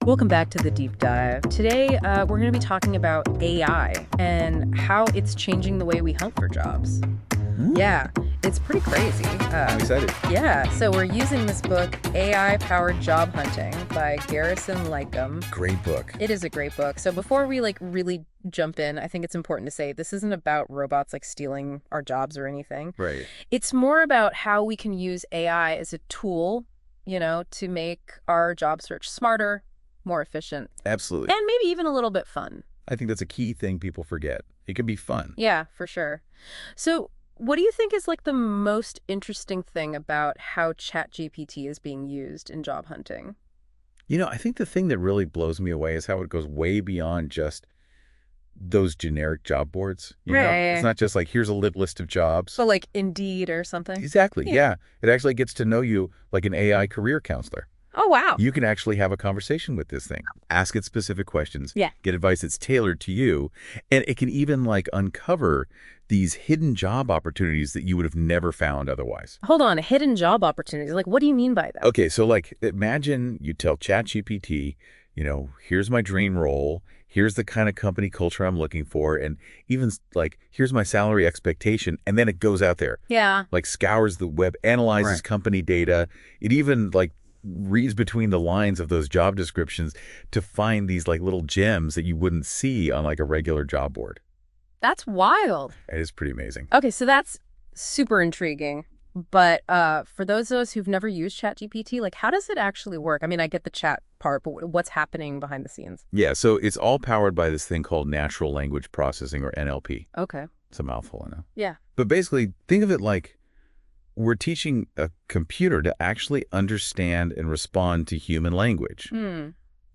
AI-Powered-Job-Search-Strategies-with-music.mp3